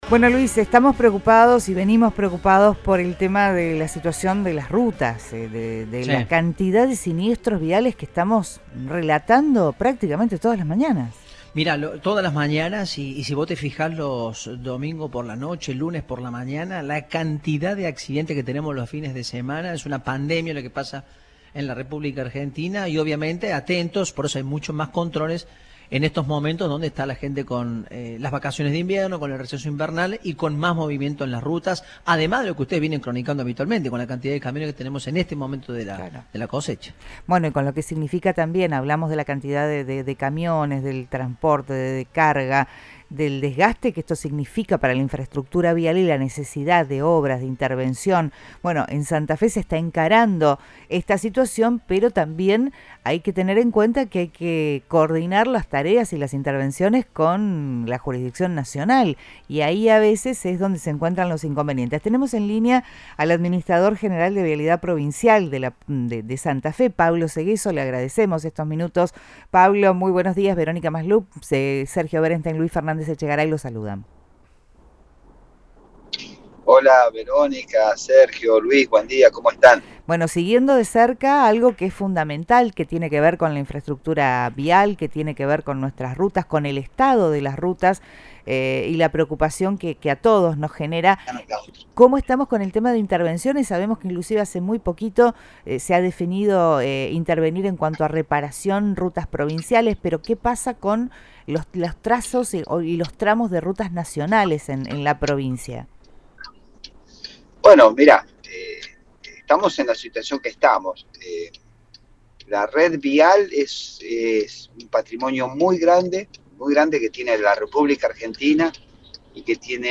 Pablo Seghezzo, administrador general de Vialidad Provincial de Santa Fe, compartió su perspectiva sobre este tema en diálogo con Radioinforme 3, por Cadena 3 Rosario.